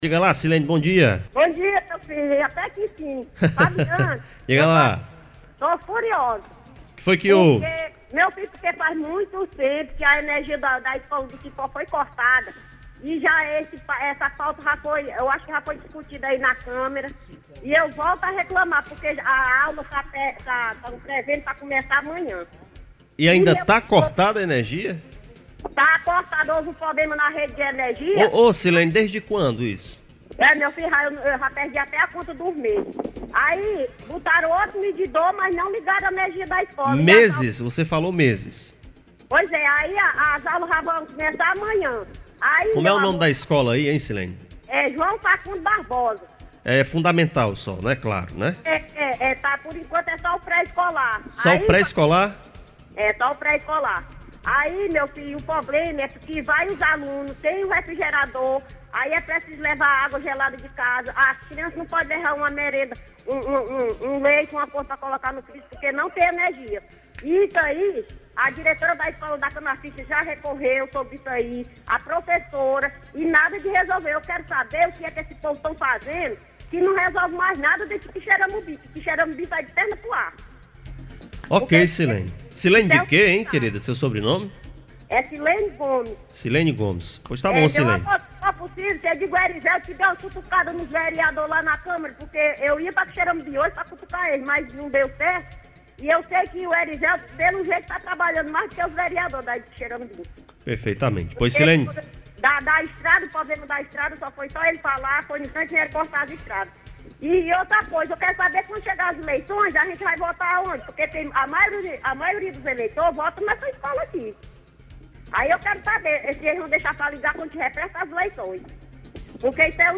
A reivindicação foi efetuada durante o Programa SerTão Conta Mais, da Rádio Campo Maior AM 840.